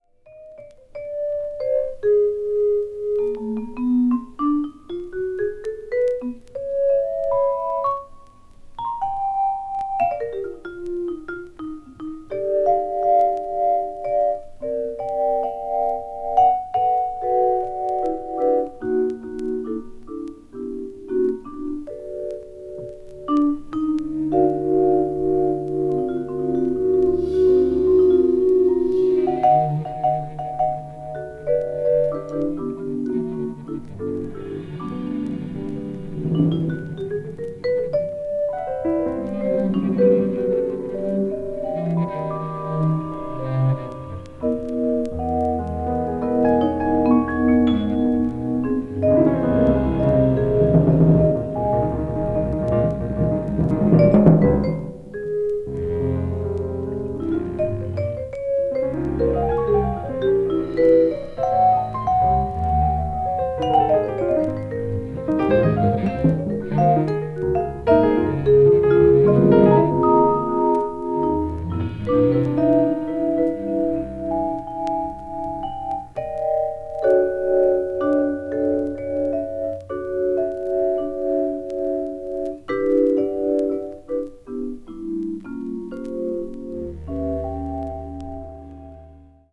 media : EX/EX(わずかにチリノイズが入る箇所あり)
ゆったりとリラックスしてお聴きいただけます。
modern jazz   post bop